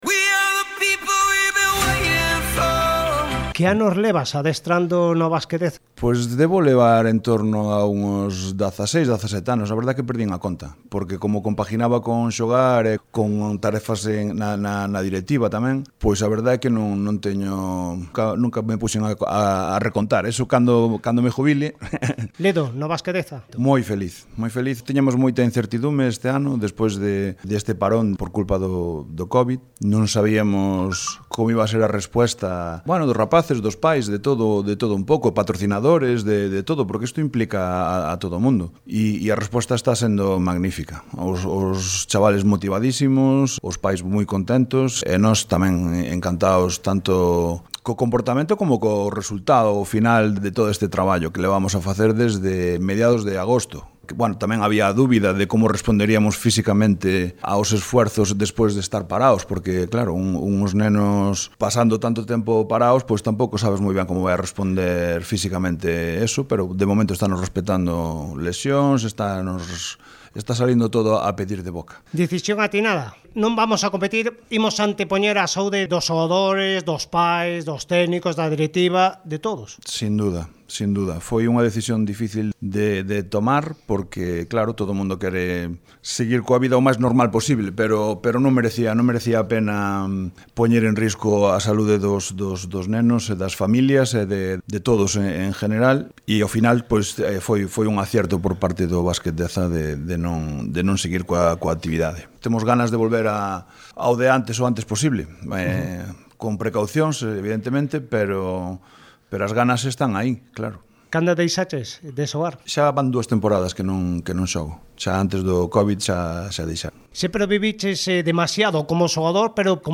Entrevistas Radio Lalín – Basketdeza